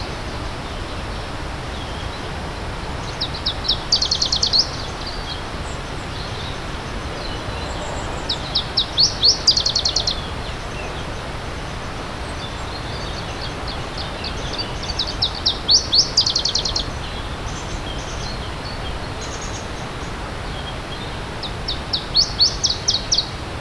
Birds.wav